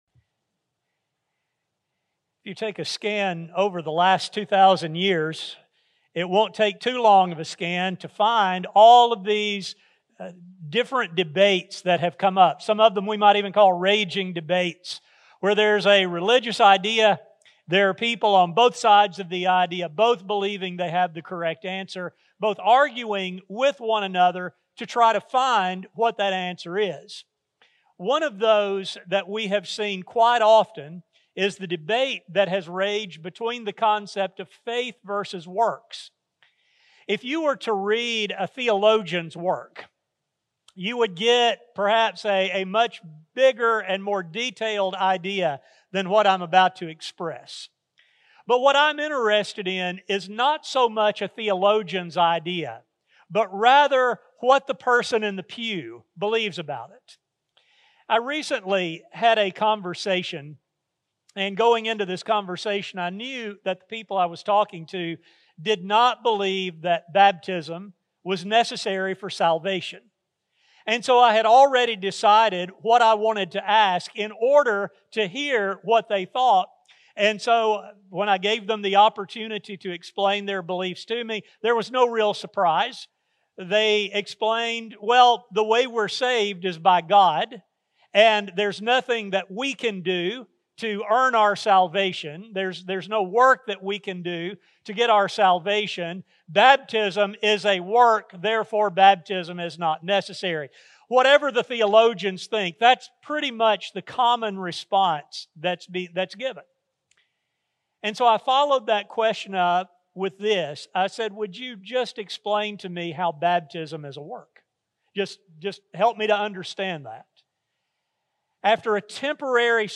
In this study, we will trace God’s salvation for the people of Israel and make the connection with our own as we explore the biblical ideas of faith and works. A sermon